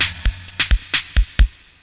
tzwdrum5.wav